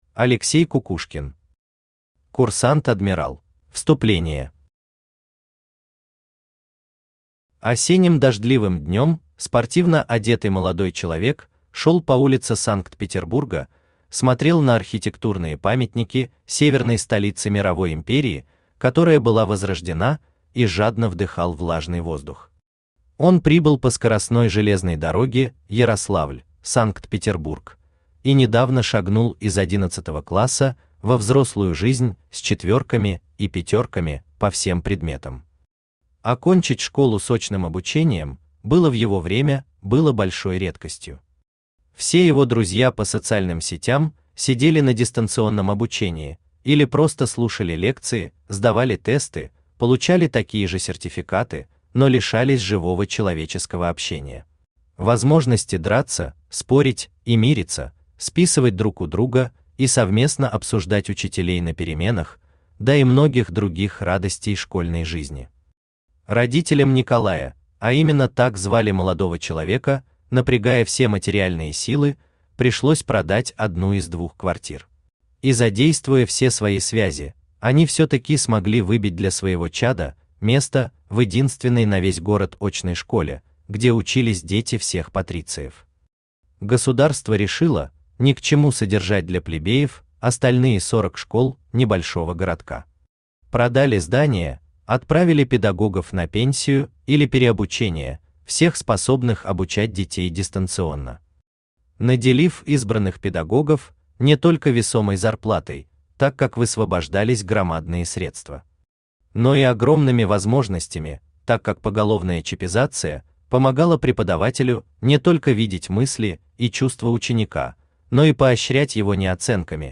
Аудиокнига Курсант-адмирал | Библиотека аудиокниг
Aудиокнига Курсант-адмирал Автор Алексей Николаевич Кукушкин Читает аудиокнигу Авточтец ЛитРес.